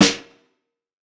TS Snare_16.wav